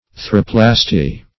Search Result for " urethroplasty" : The Collaborative International Dictionary of English v.0.48: Urethroplasty \U*re"thro*plas`ty\, n. [Urethra + -plasty.]